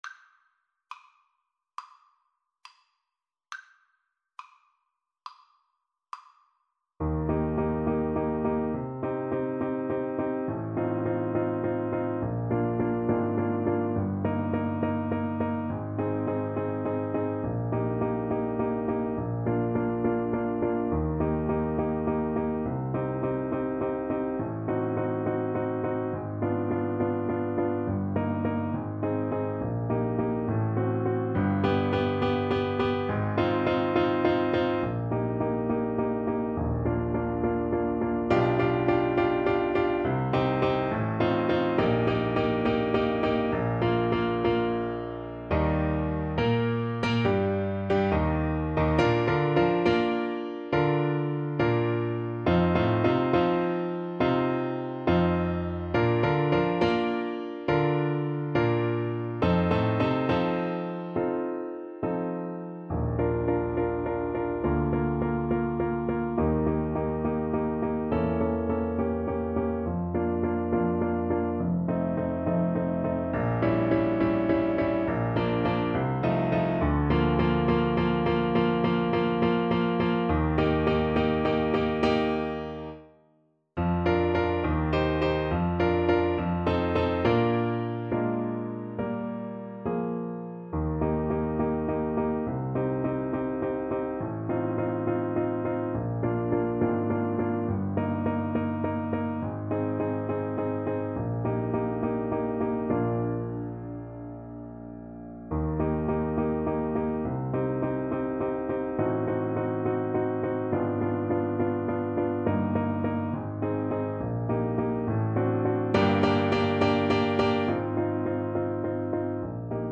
Classical (View more Classical Saxophone Music)